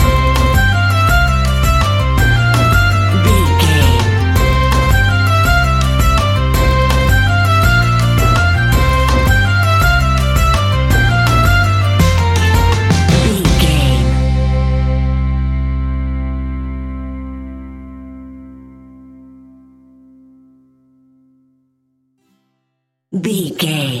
Mixolydian
sea shanties
acoustic guitar
mandolin
double bass
accordion